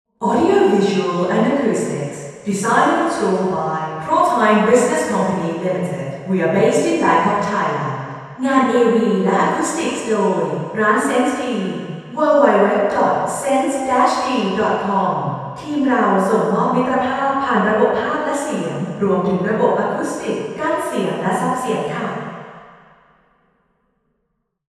Room: Vethes Samosorn, Ministry of Foreign Affairs
Test Position 4: 32 m